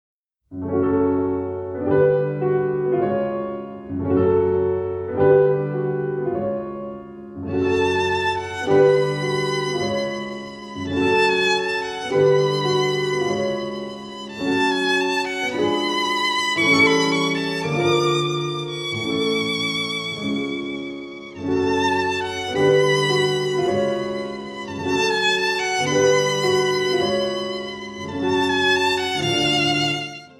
Violin
in concert and in studio